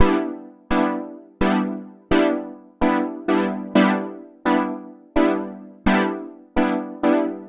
Retro Pads.wav